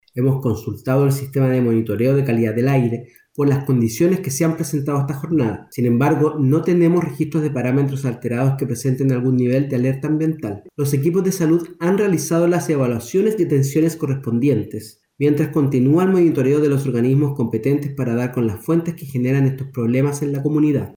En conversación con Radio Bío Bío, Cristian Cáceres, delegado de la denominada ‘zona de sacrificio’, indicó que “hemos consultado el sistema de monitoreo de calidad del aire por las condiciones que se han presentado esta jornada”.